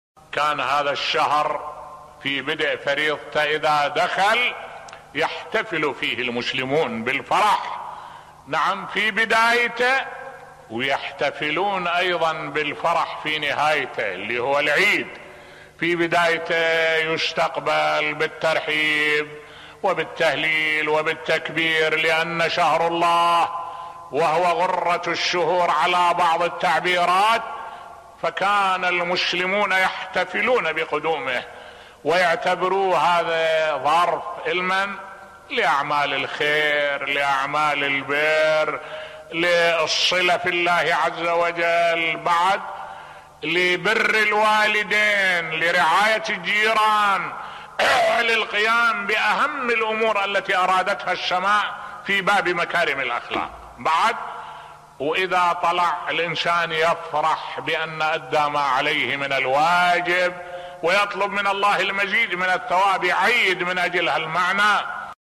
ملف صوتی خصوصية شهر رمضان لدى المسلمين بصوت الشيخ الدكتور أحمد الوائلي